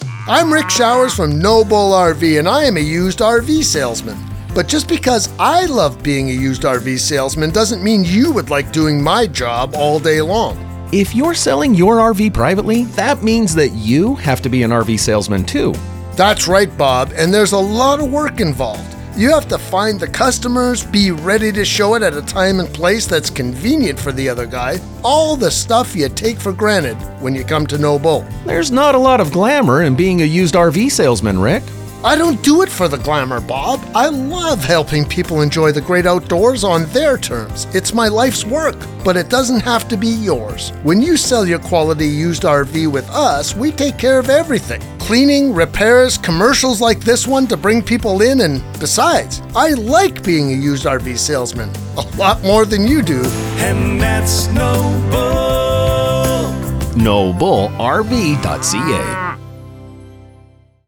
2026 Radio Ads